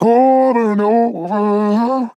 FORM over and over Vocal Sample
Categories: Vocals
man-disco-vocal-fills-120BPM-Fm-1.wav